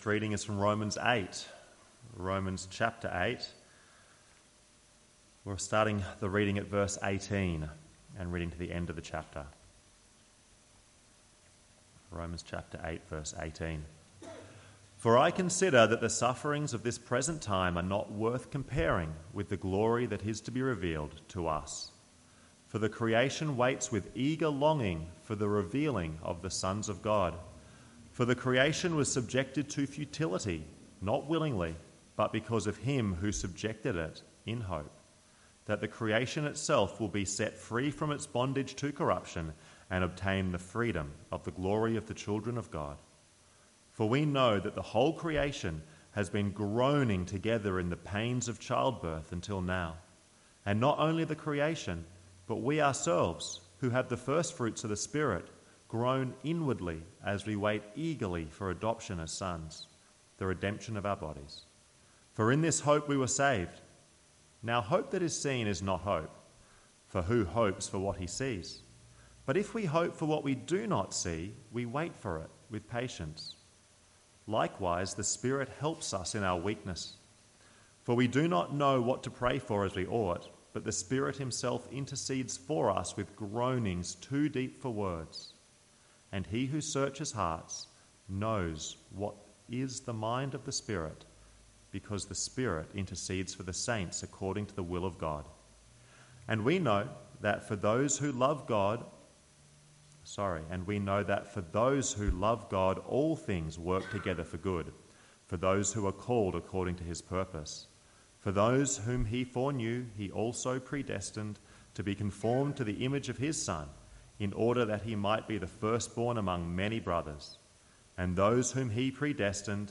2024 Launch Sermon Player Romans 8